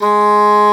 Index of /90_sSampleCDs/Roland L-CDX-03 Disk 1/WND_English Horn/WND_Eng Horn Sh